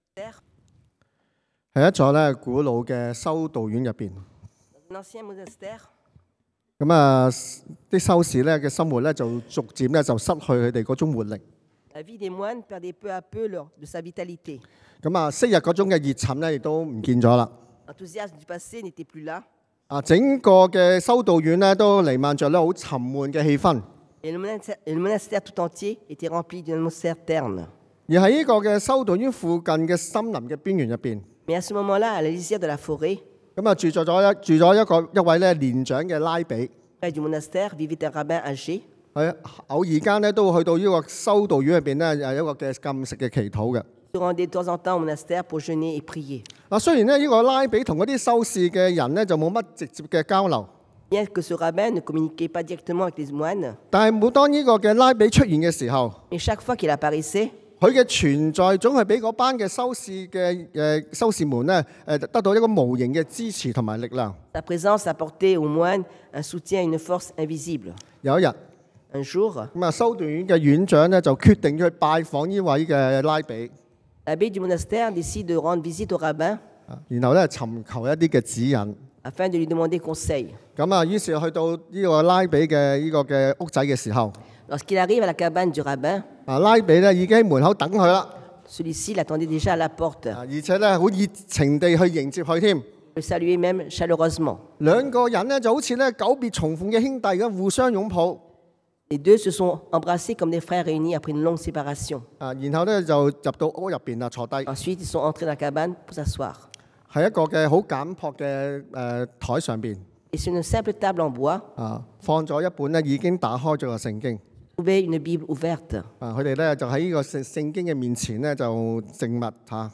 Passage: Jean 約翰福音 1:1-14 Type De Service: Predication du dimanche